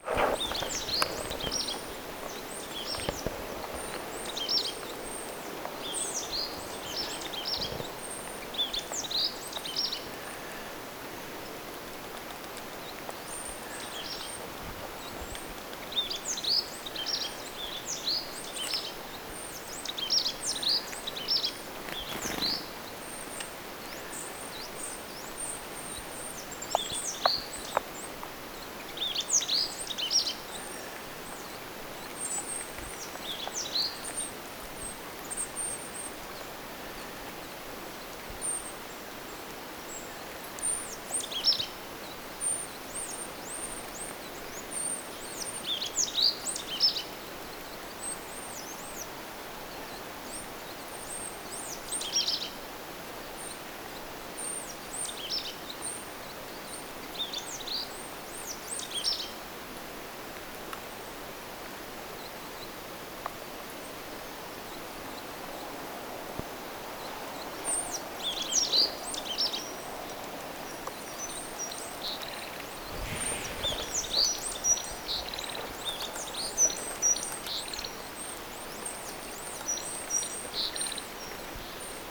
hienoa ja erikoista
kahden hömötiaislinnun laulua
hienoa_kahden_homotiaislinnun_laulua.mp3